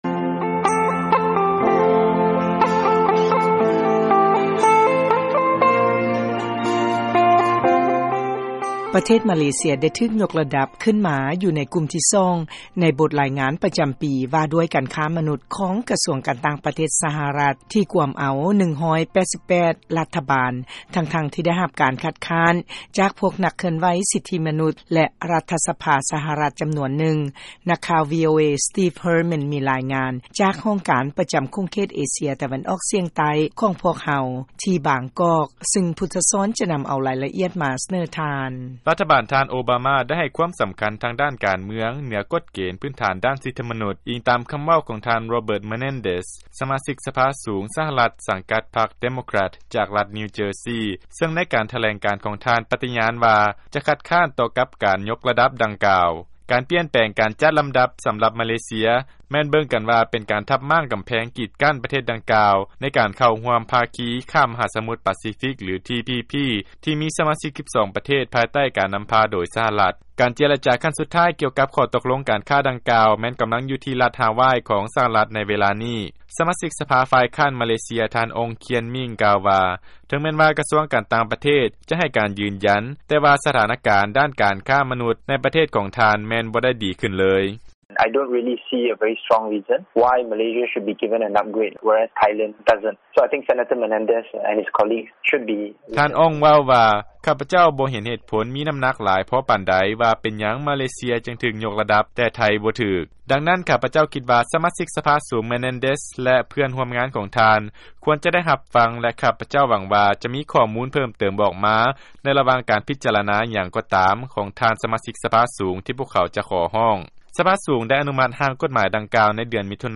ມີລາຍງານຈາກ ຫ້ອງການປະຈຳເຂດ ເອເຊຍຕາເວັນອອກສຽງ ໃຕ້ ຂອງພວກເຮົາ ທີ່ບາງກອກ